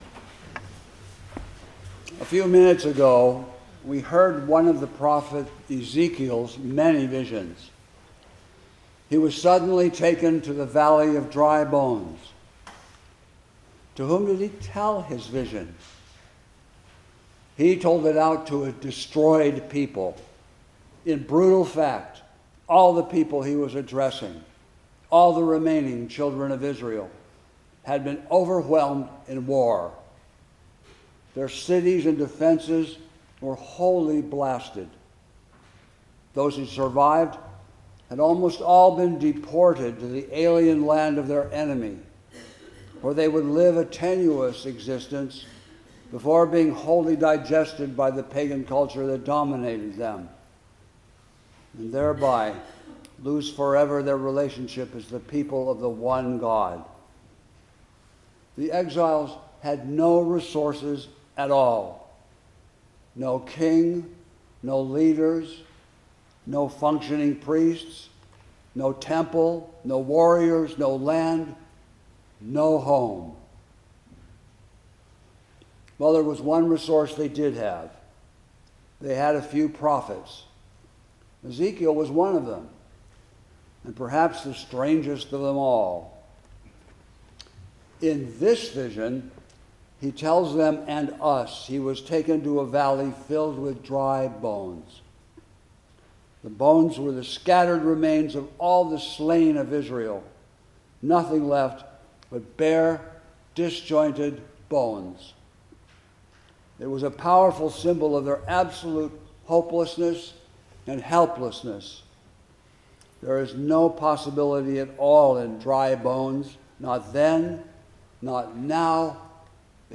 Sermon-Great-Vigil-of-Easter-April-4-2026.mp3